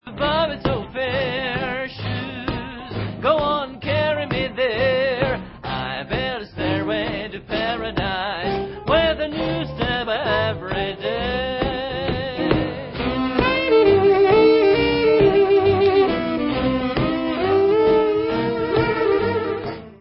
sledovat novinky v oddělení Blues/Swing